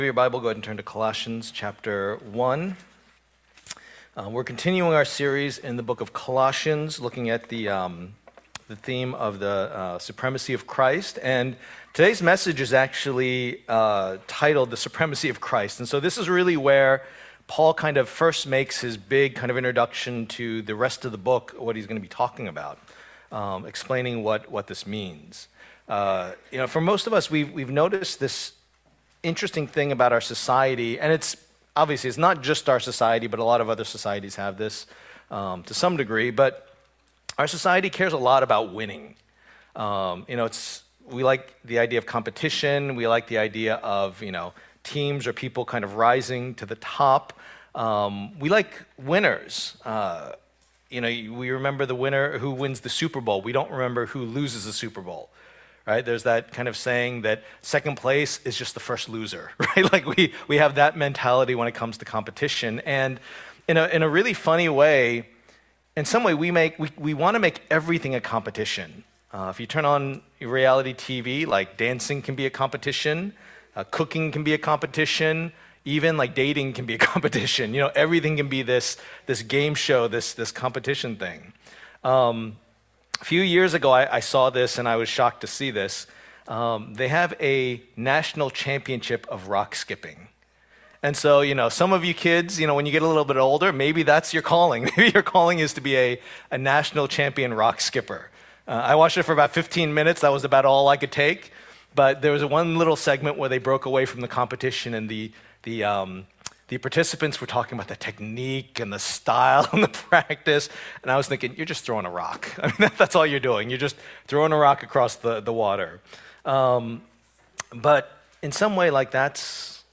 Preacher
Passage: Colossians 1:15-23 Service Type: Lord's Day